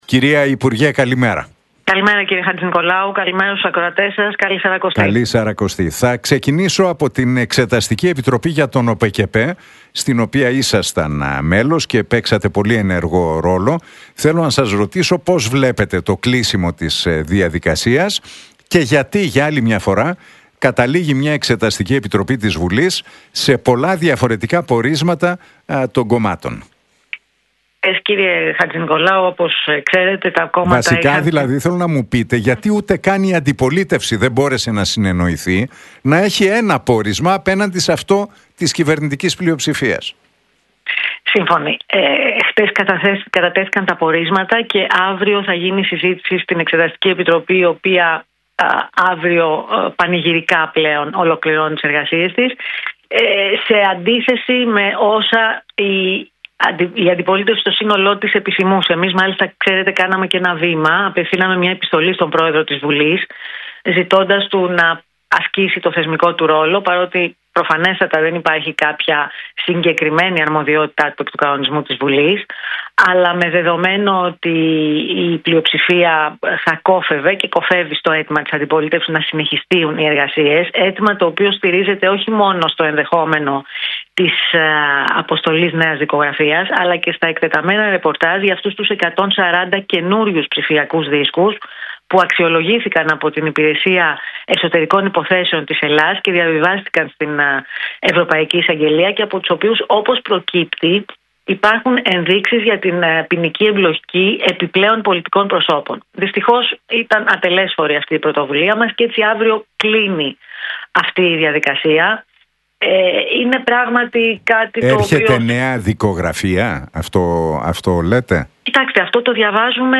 Για την Εξεταστική Επιτροπή για τον ΟΠΕΚΕΠΕ και το κλείσιμο της διαδικασίας μίλησε στον Realfm 97,8 και την εκπομπή του Νίκου Χατζηνικολάου, η βουλευτής του ΠΑΣΟΚ, Μιλένα Αποστολάκη.